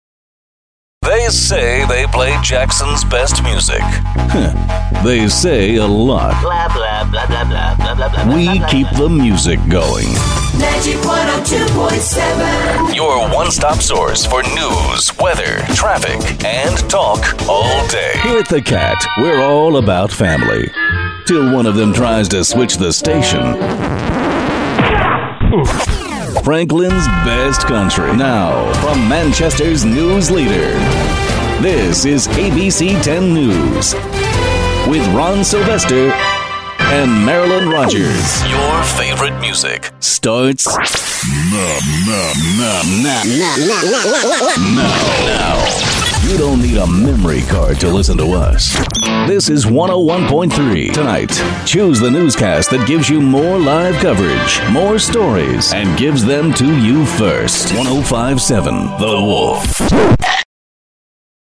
Imaging Demo